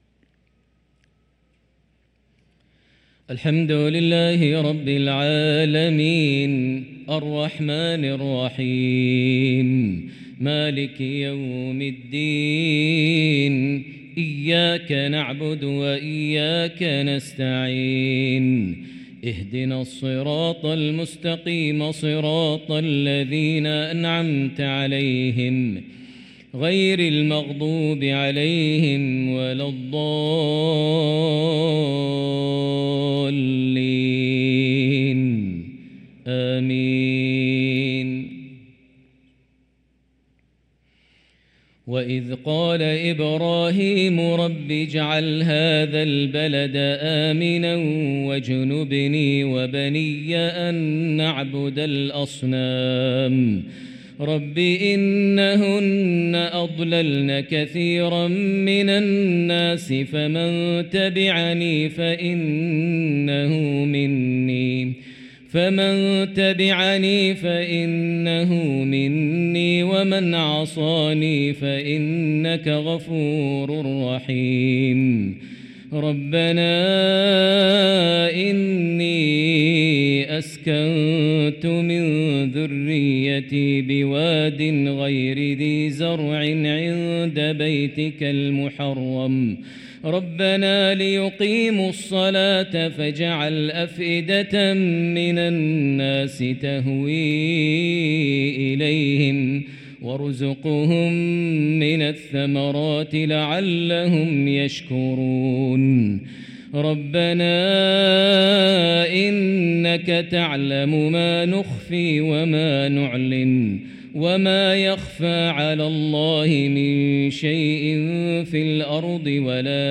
صلاة العشاء للقارئ ماهر المعيقلي 5 ربيع الآخر 1445 هـ
تِلَاوَات الْحَرَمَيْن .